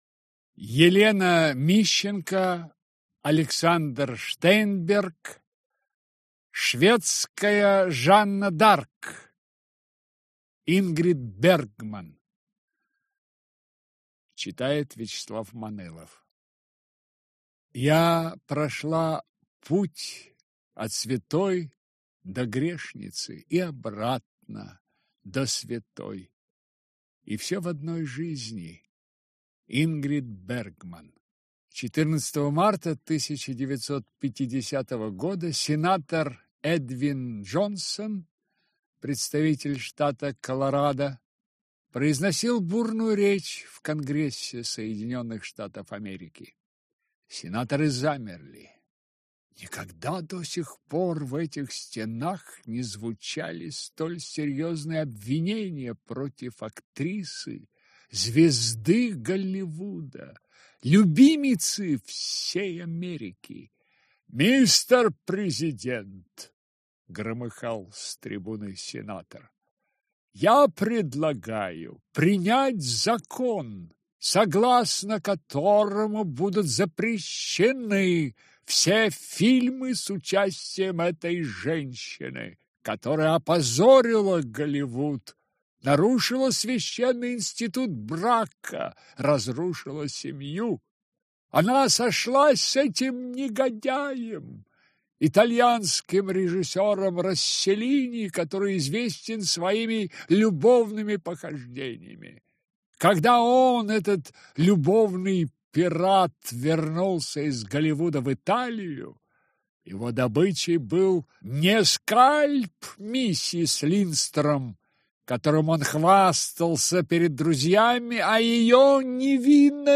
Аудиокнига Шведская Жанна д’Арк. Ингрид Бергман - купить, скачать и слушать онлайн | КнигоПоиск